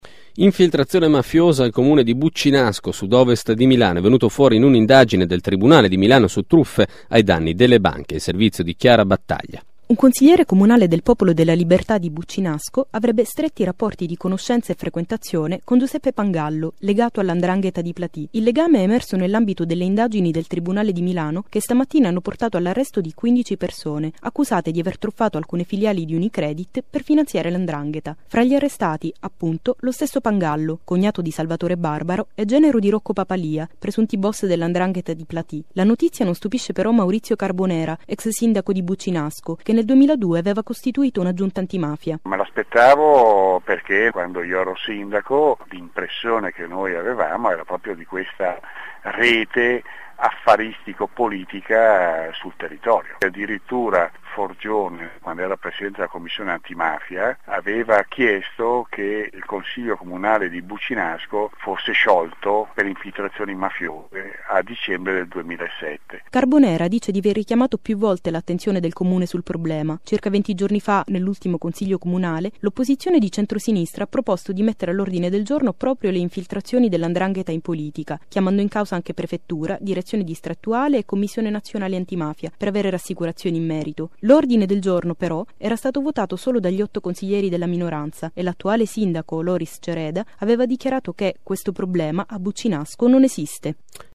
notiziarioradiopopo1930.mp3